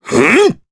Crow-Vox_Attack2_jp.wav